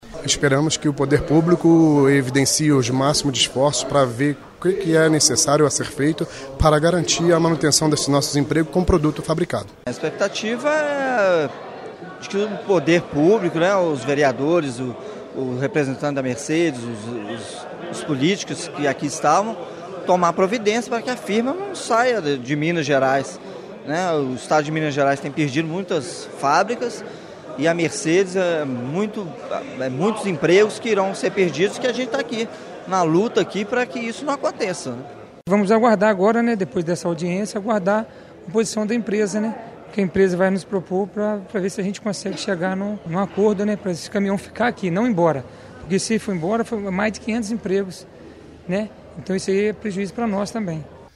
A reportagem da FM Itatiaia conversou com os trabalhadores.
depoimentos de trabalhadores